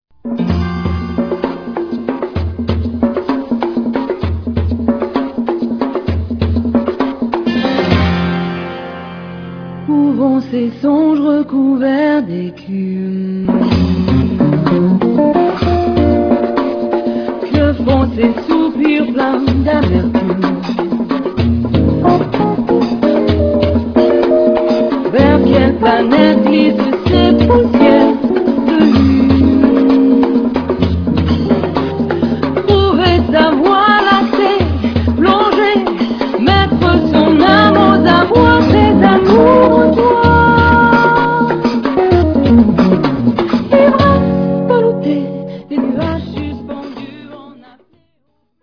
13 titres réalisés au studio du Flon à Lausanne